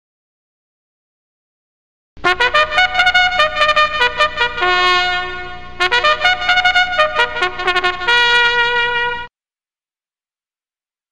Trumpet Fanfare